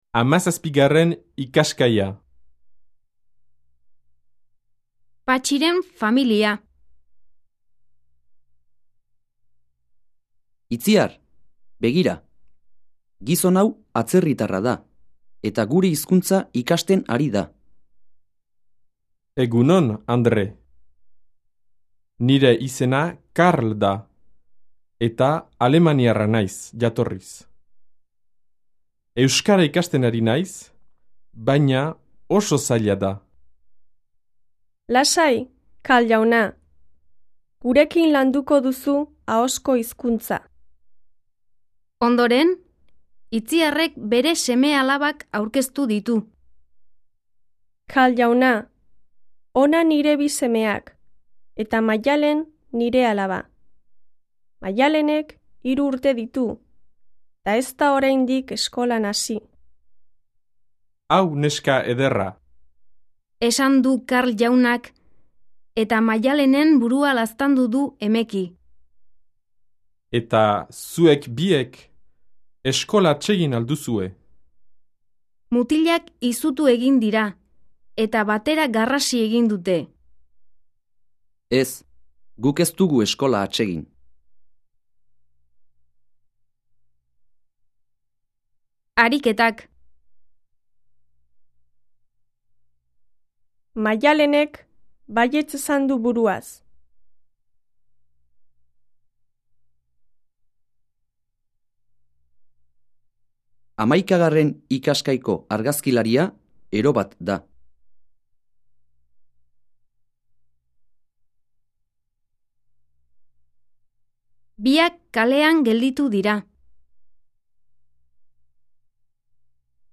Диалог